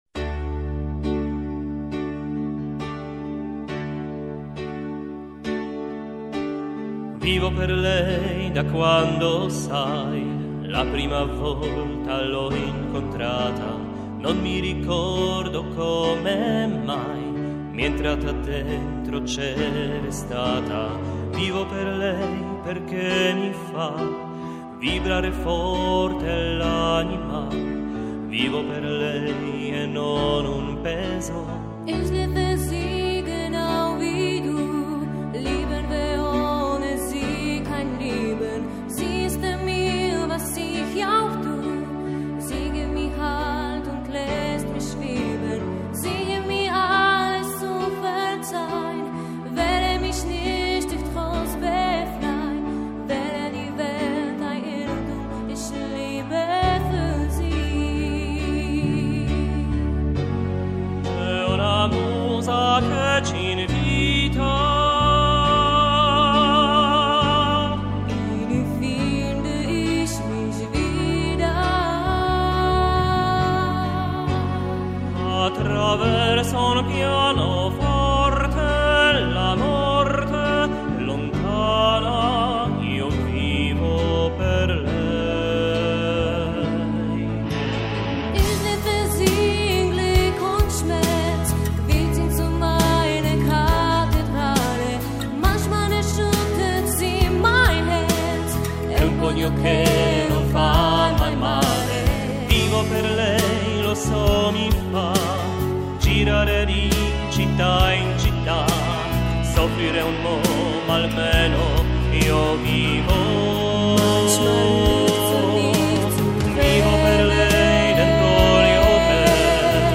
mezzosopran
tenor